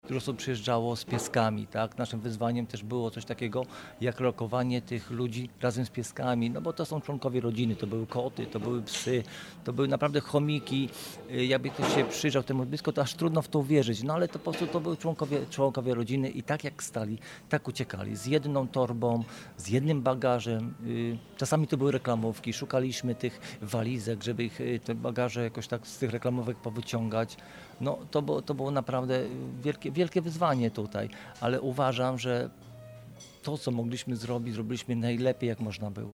Nadajemy program prosto z Dworca Głównego PKP we Wrocławiu, z kawiarni Stacja Dialog.